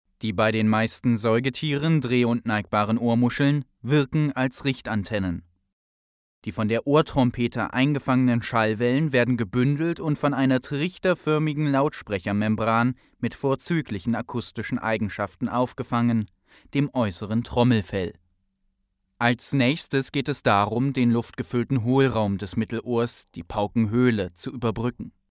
deutscher Profi - Sprecher für Firmenpräsentationen, Hörbuchproduktionen, Radio
Kein Dialekt
Sprechprobe: eLearning (Muttersprache):
german voice over talent